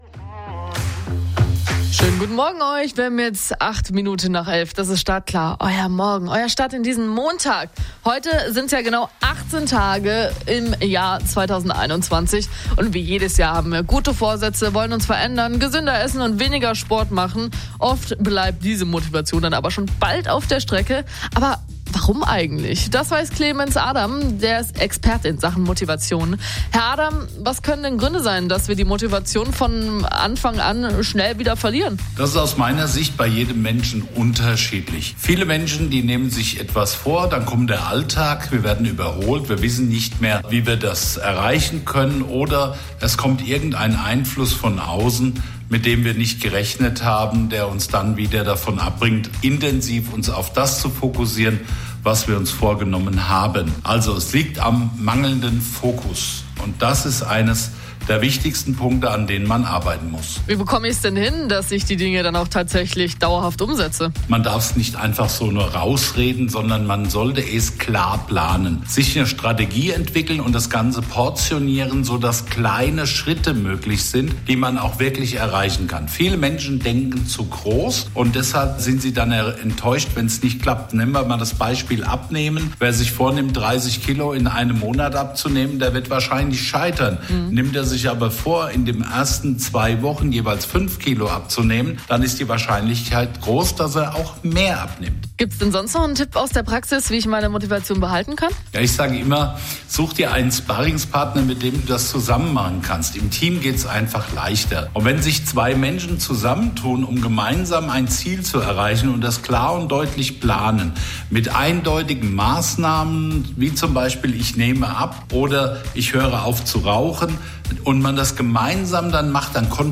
Anfrage für Radio- oder TV-Gespräch Jetzt Anfragen Hörprobe von Radio Neckaralb Live Expertenbeschreibung Downloaden Download Referenzen ansehen und downloaden Zum Video